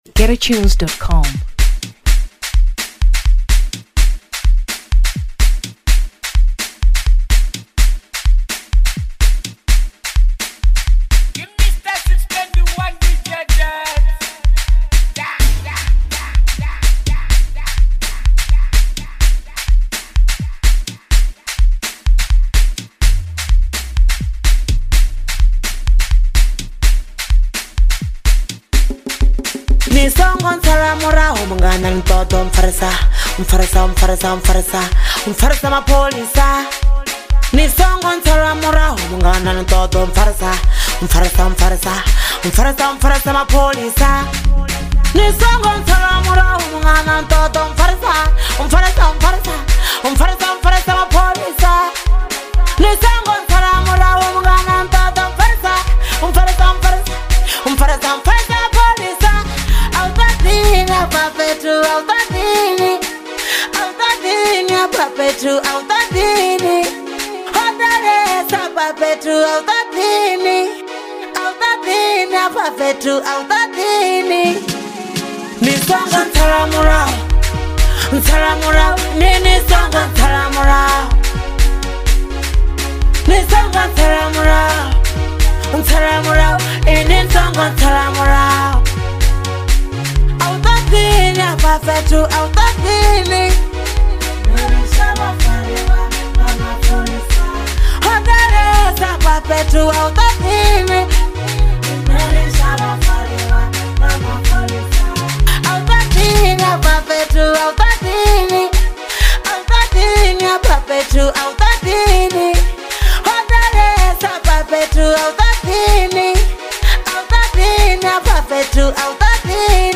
Amapiano 2023 South Africa